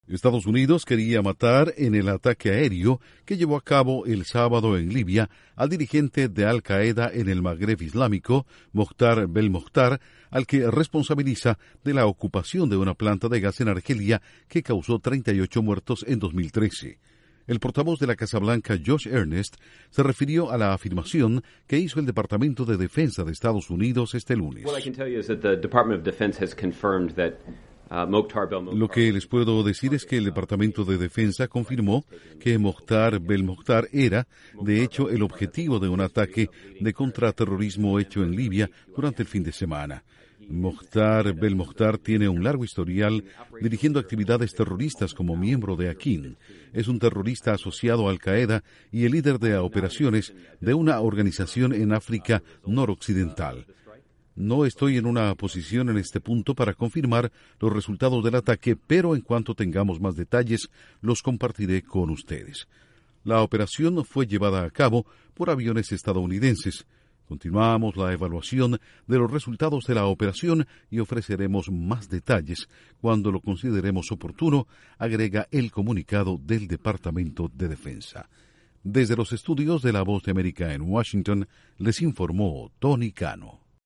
Casa Blanca confirma comunicado del Departamento de Defensa afirma que EEUU quería matar al líder de al-Qaeda en Libia, Mokhtar Bel Mokhtar. Informa desde los estudios de la Voz de América en Washington